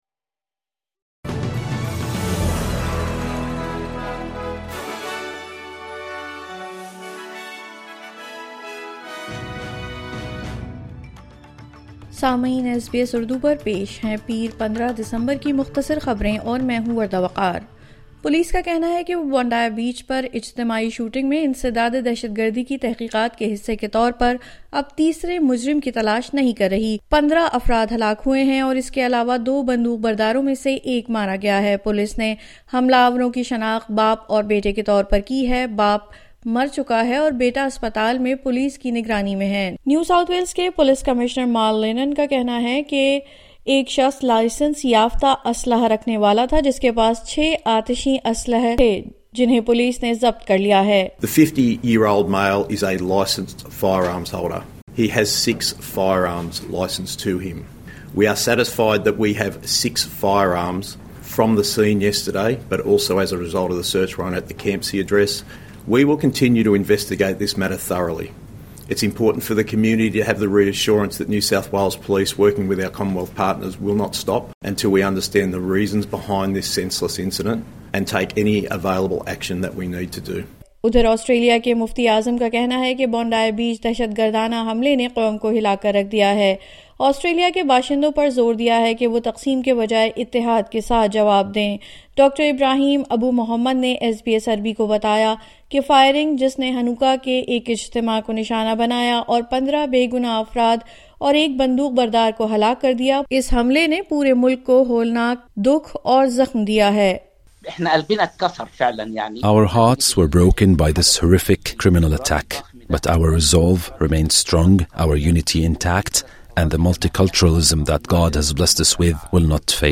مختصر خبریں : بدھ 17 دسمبر 2025